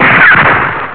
snd_5566_gunshot.wav